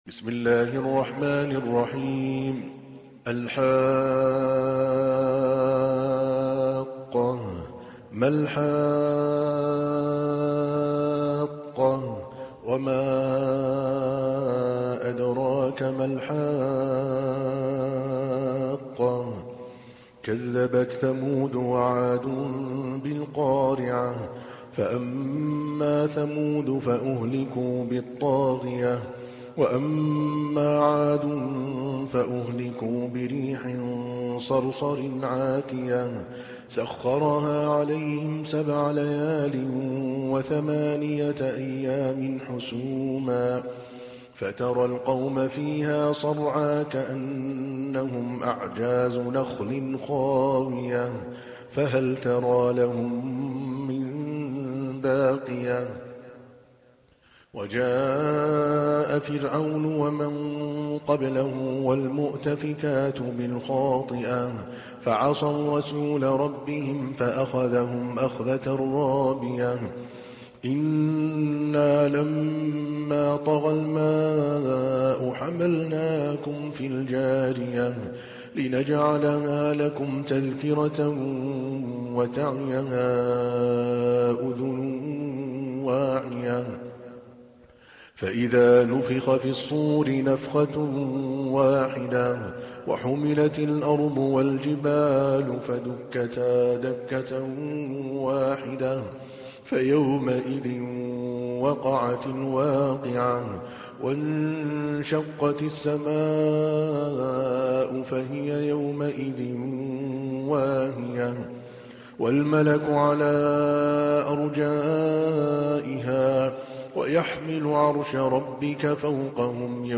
Surah Repeating تكرار السورة Download Surah حمّل السورة Reciting Murattalah Audio for 69. Surah Al-H�qqah سورة الحاقة N.B *Surah Includes Al-Basmalah Reciters Sequents تتابع التلاوات Reciters Repeats تكرار التلاوات